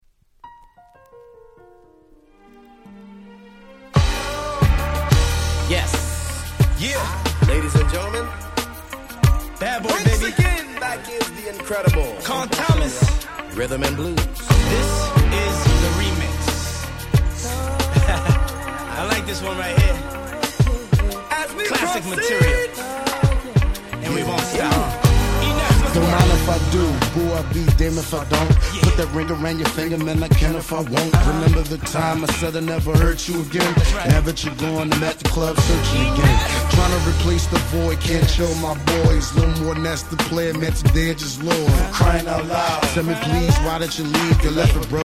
90's感溢れるいなたいHip Hop Soul !!